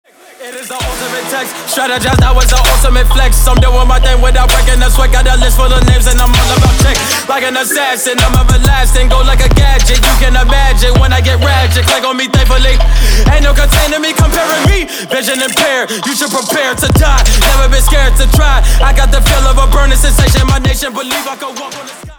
• Качество: 256, Stereo
громкие
мощные
Хип-хоп
Trap